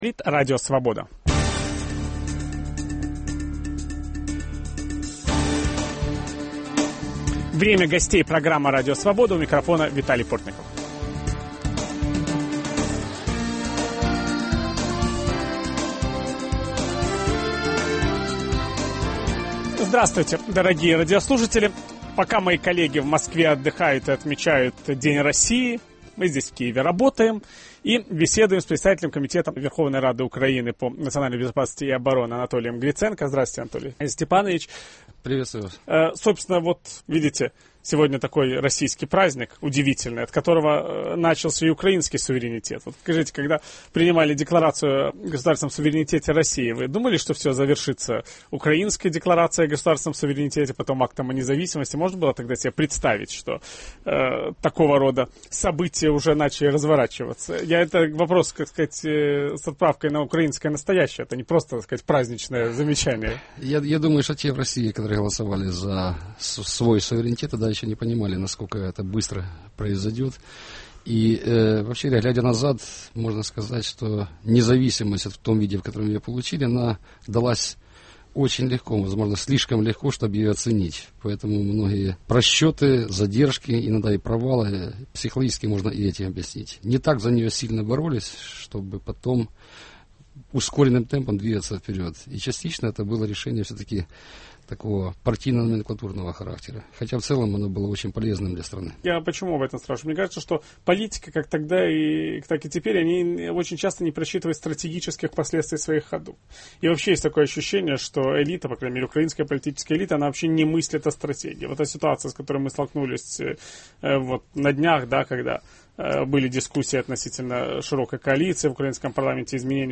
Почему председатель комитета Верховной Рады Украины по нацбезопасности и обороне, бывший министр обороны страны Анатолий Гриценко решил баллотироваться в президенты? Как он оценивает украинскую политическую ситуацию? С Анатолием Гриценко беседует Виталий Портников.